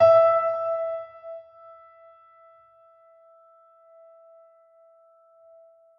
piano-sounds-dev
e4.mp3